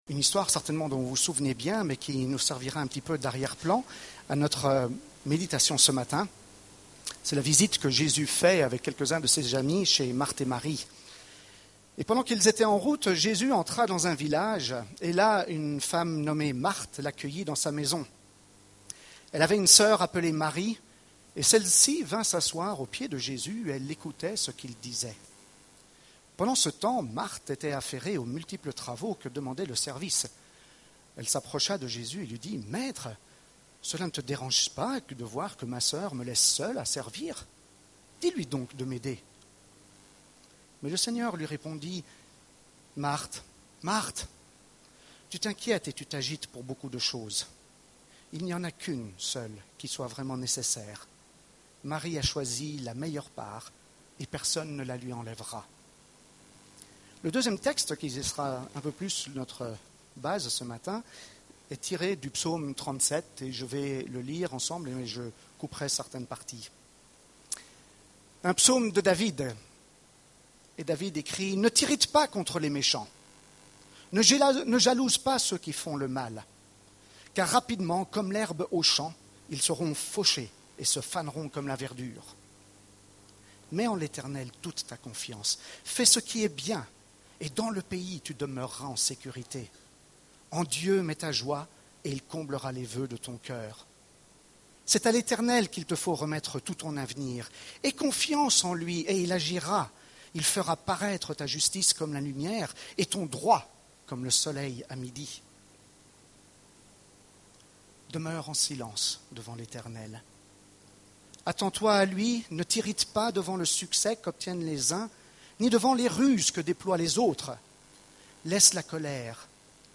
Culte du 24 juin